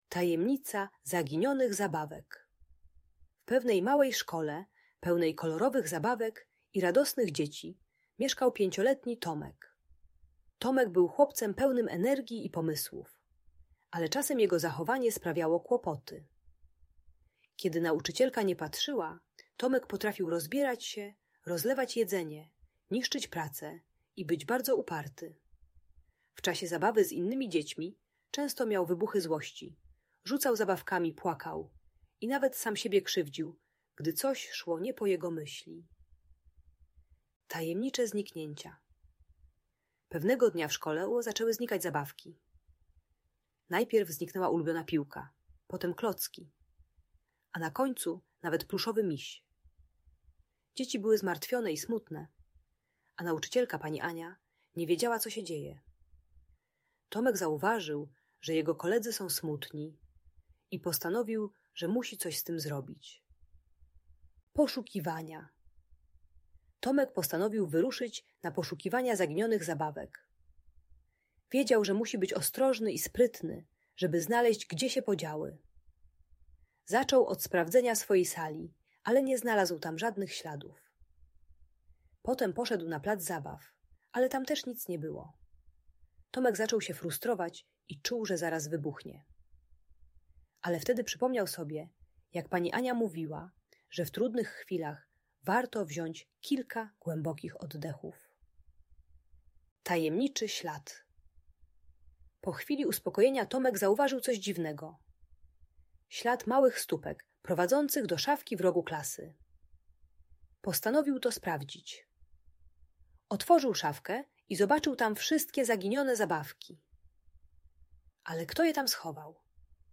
Tajemnica Zaginionych Zabawek - Przedszkole | Audiobajka
Audiobajka uczy techniki głębokiego oddychania w momentach frustracji oraz pokazuje wartość współpracy z rówieśnikami.